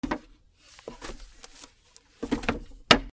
/ M｜他分類 / L01 ｜小道具 /
木のポスト
『カタンパタ』